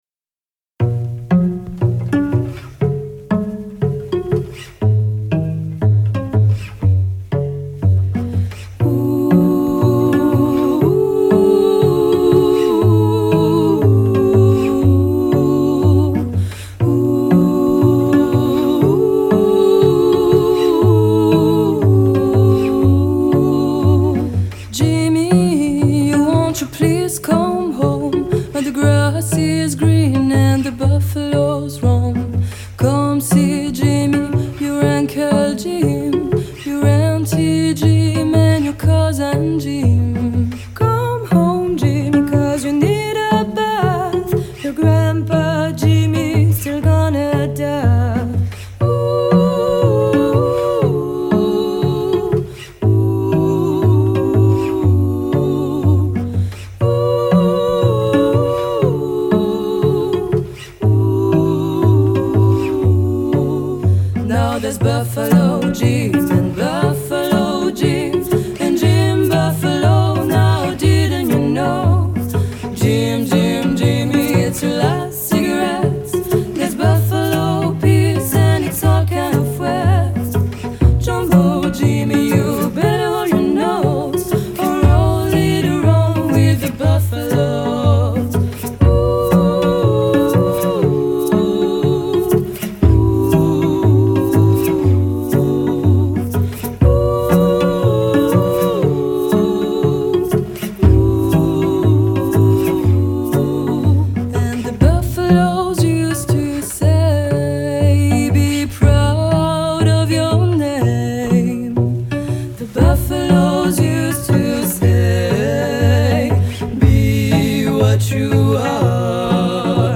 Строго, без излишеств – вокал, виолончель и барабан.
Genre: French Music, Pop